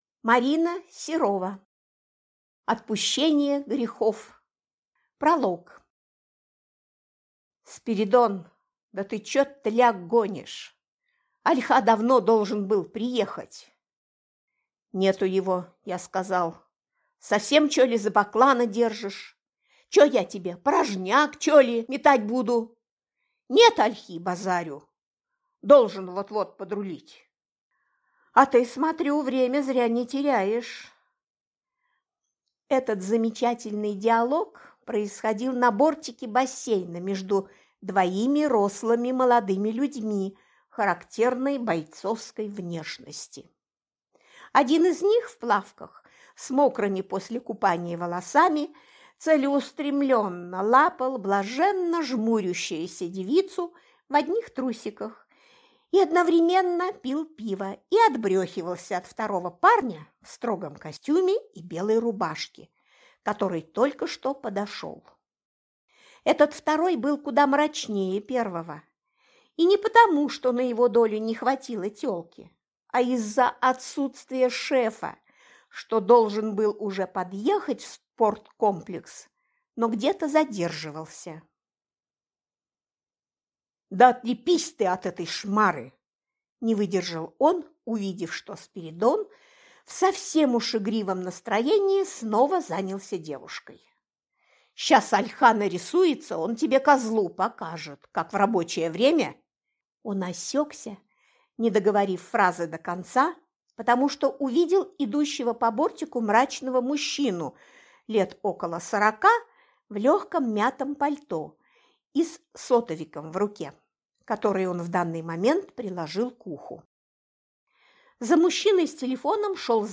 Аудиокнига Отпущение грехов | Библиотека аудиокниг
Прослушать и бесплатно скачать фрагмент аудиокниги